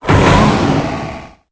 Cri_0812_EB.ogg